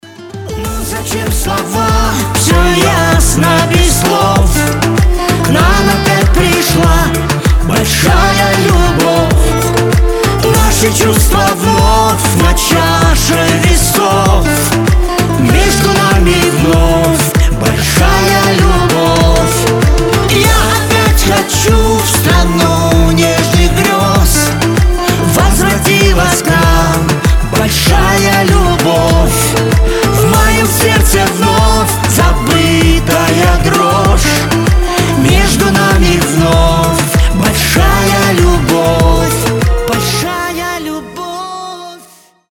дуэт
эстрадные